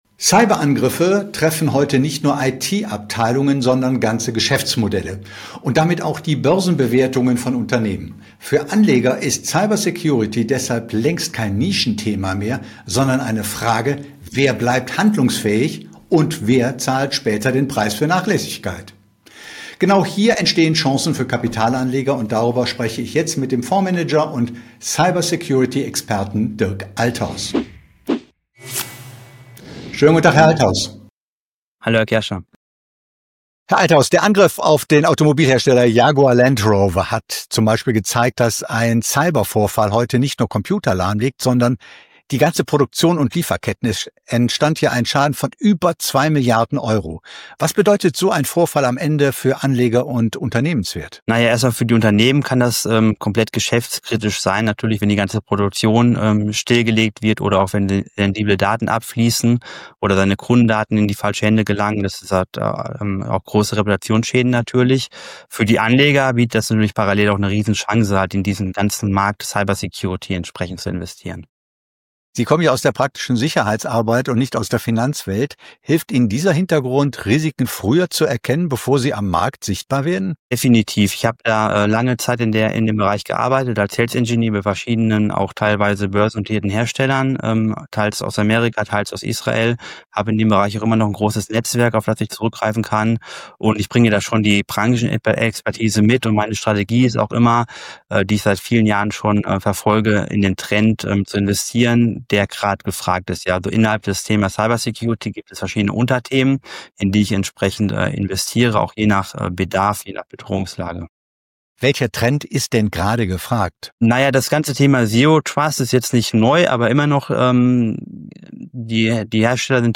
Fundierte Finanzanalysen und exklusive Experteninterviews